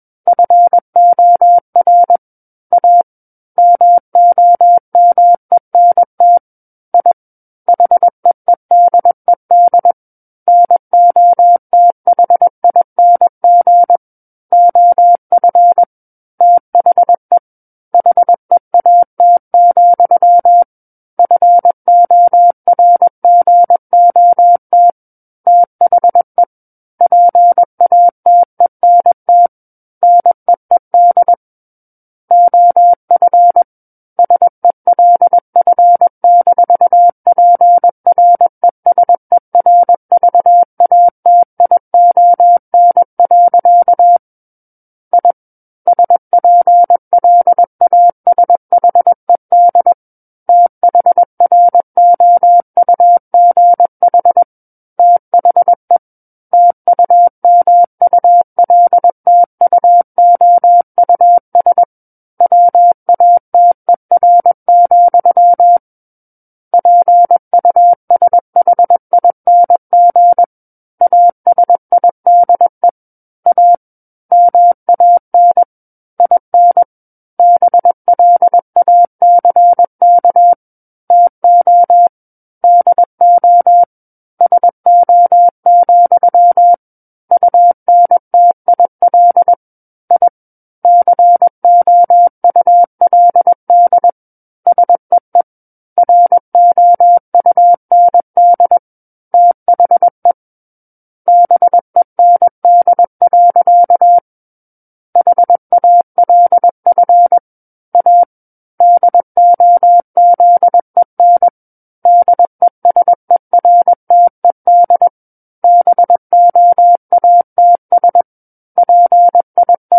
War of the Worlds - 12-Chapter 12 - 21 WPM